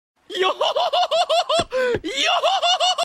Brook Laughter Sound Effect Free Download
Brook Laughter